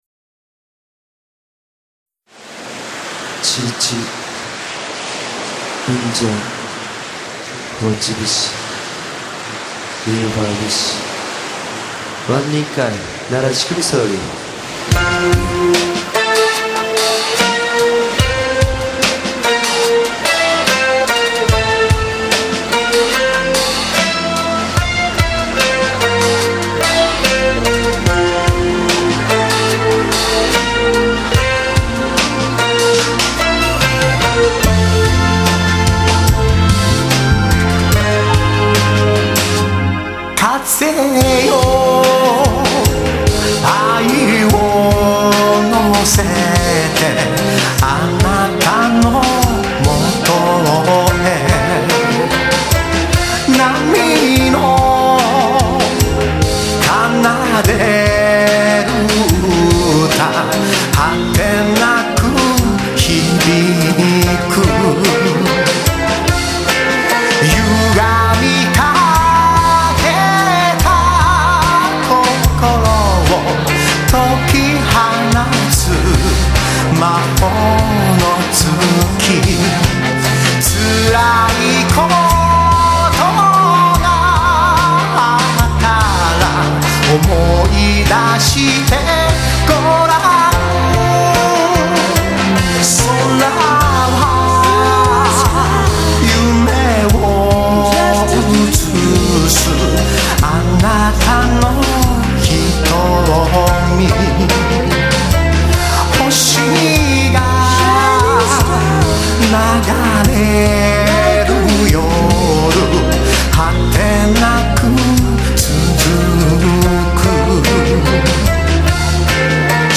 沖縄音楽をルーツに　新たなワールドミュージックの伝説が生まれようとしている！！
沖縄音楽をベースに、ロック、ポップス、Ｒ＆Ｂなど様々な要素をミックスし、
以上の音源は、2004年度にデモ用として収録された作品ですが、歌＆センス・グルーヴにつきましては、